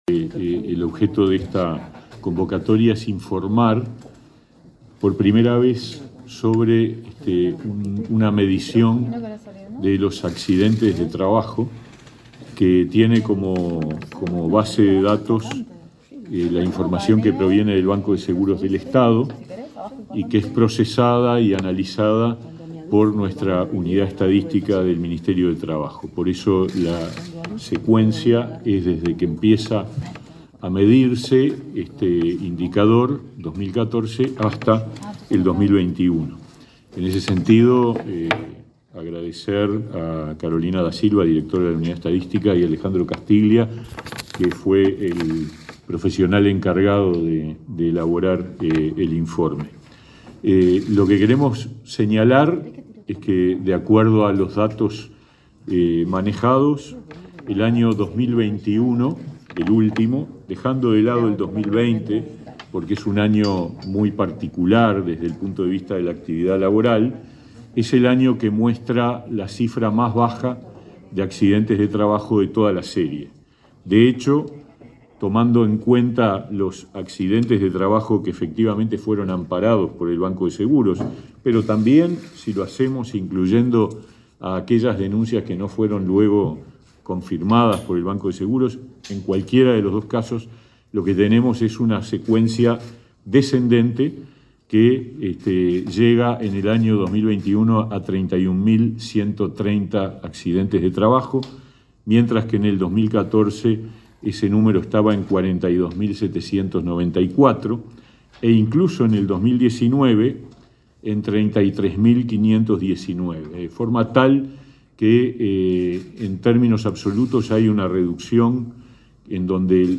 Conferencia de autoridades del Ministerio de Trabajo